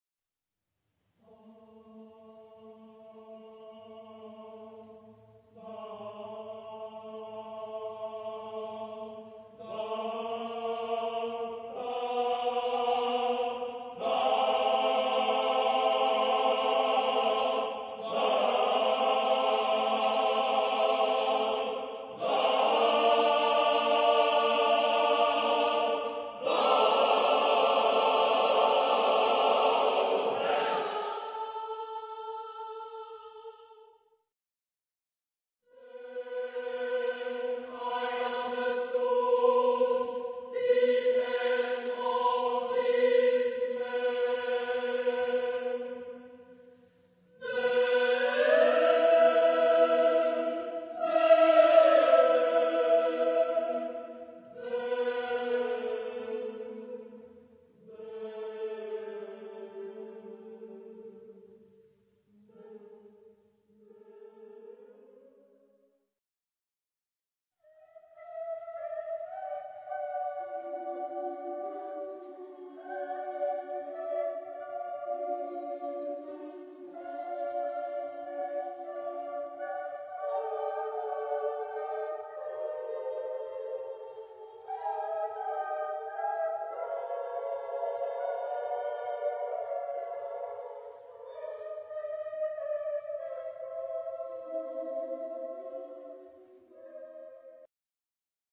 Época : Siglo 20
Tipo de formación coral: SATB  (4 voces Coro mixto )